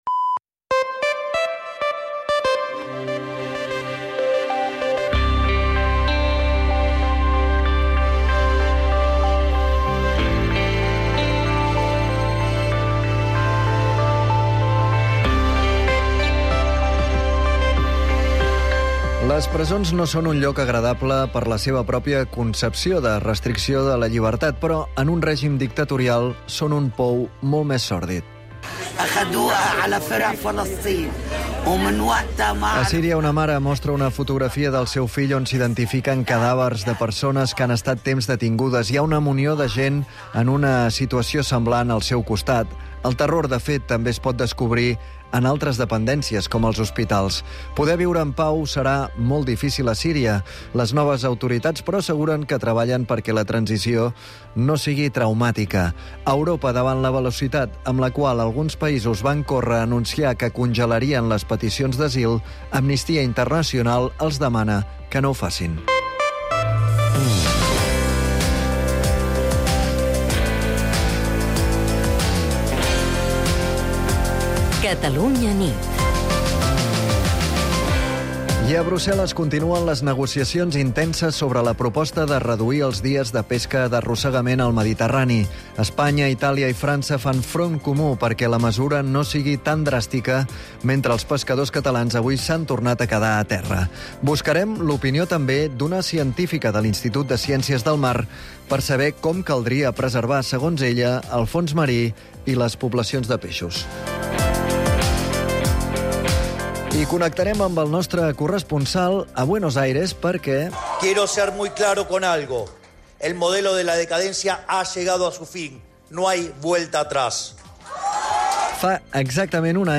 l'informatiu nocturn de Catalunya Ràdio